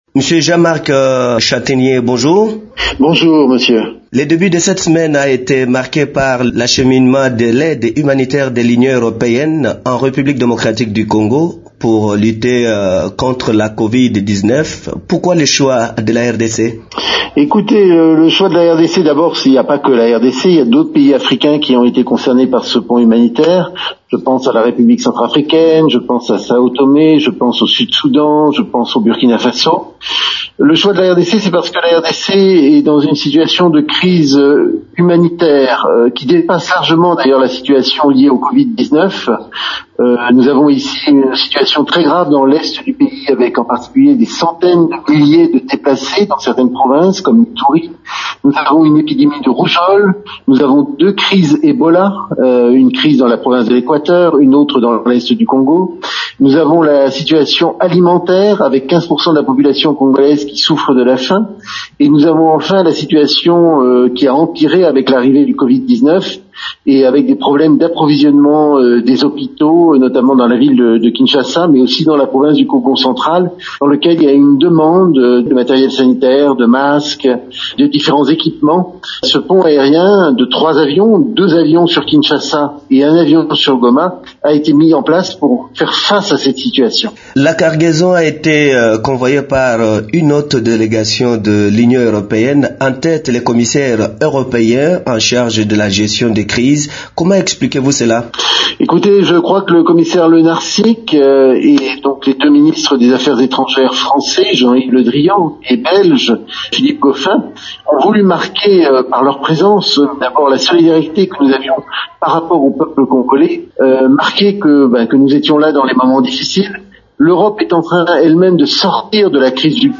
Entretien :